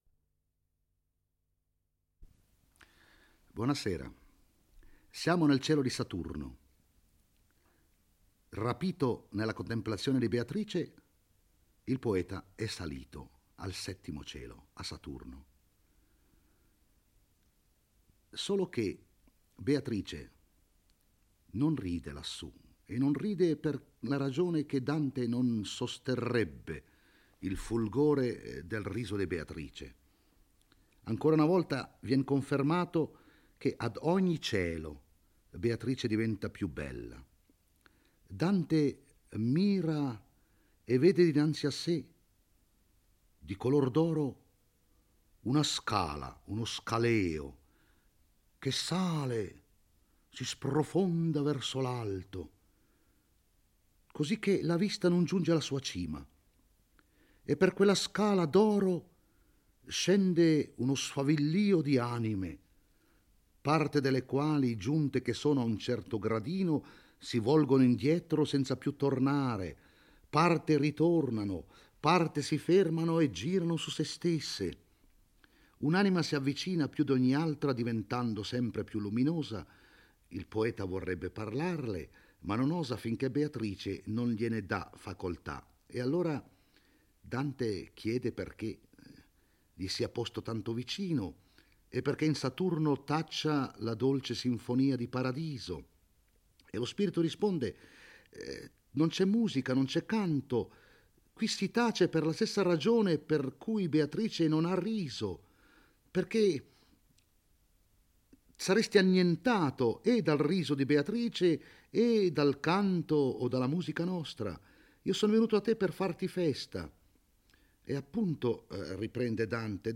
legge e commenta il XXI canto del Paradiso. Beatrice spiega al poeta che sono ormai giunti nel cielo di Saturno dove si trovano gli spiriti contemplanti.